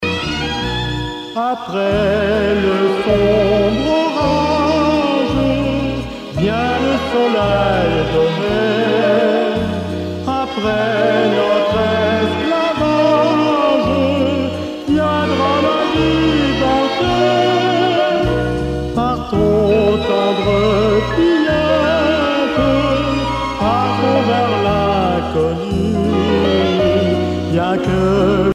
danse : valse
Genre strophique
Pièce musicale éditée